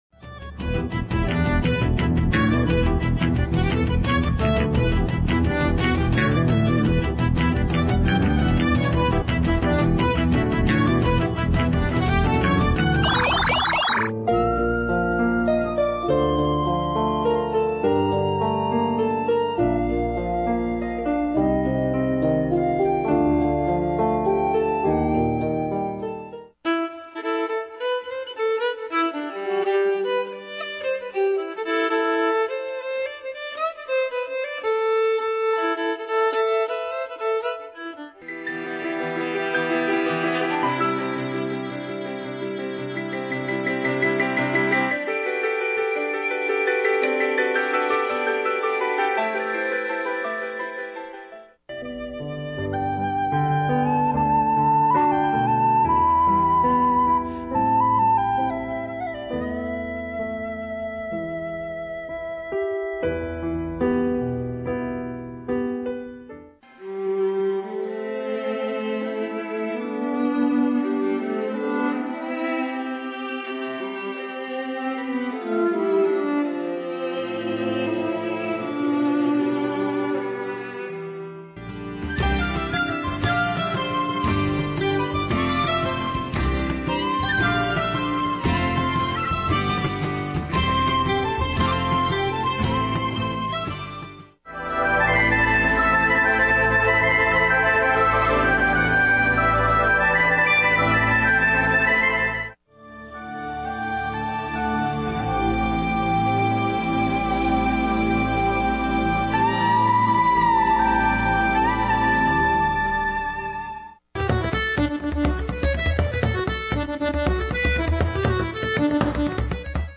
rhythms and plaintive melodies of this river dance-inspired
Irish flutes, bagpipes,
accordions, guitar and percussion will have you dancing
Instrumental